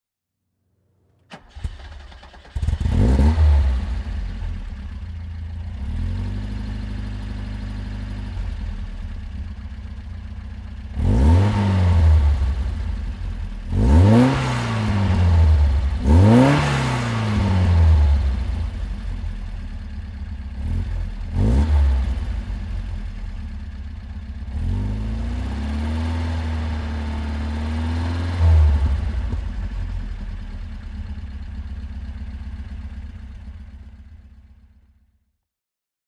Alfa Romeo 2000 GTV (1973) - Starten und Leerlauf